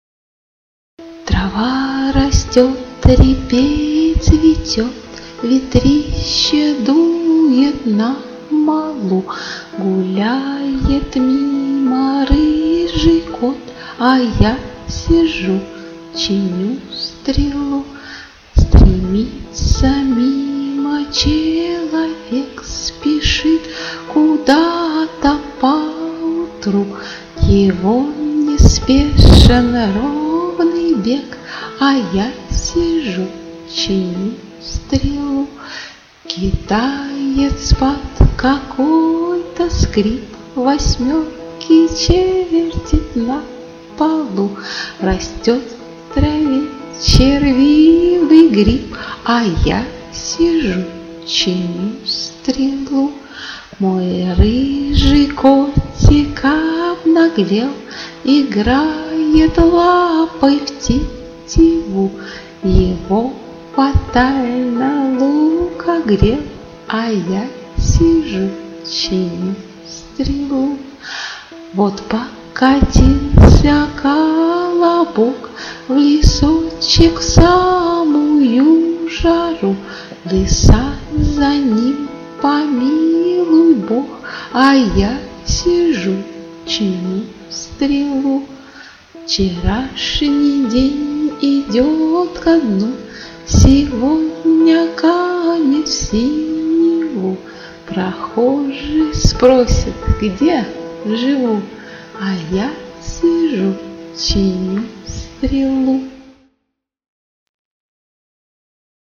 • Качество: 320, Stereo
баллада